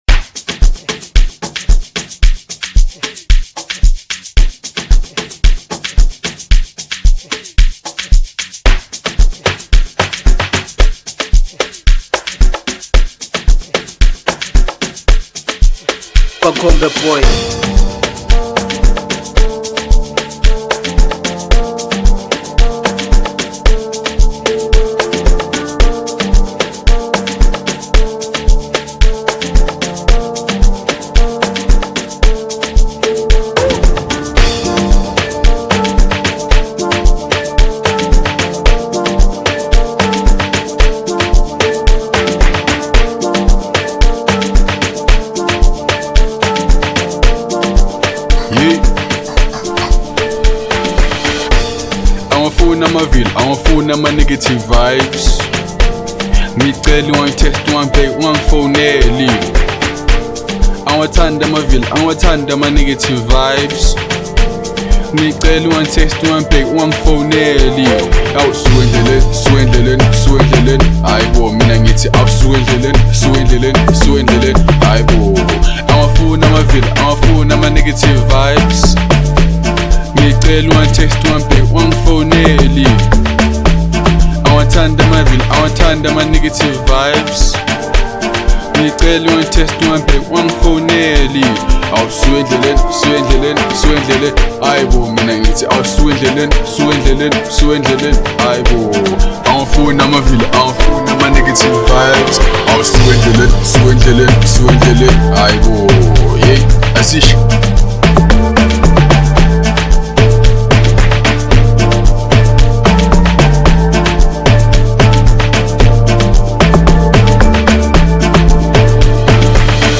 It's a very god catchy Amapiano song.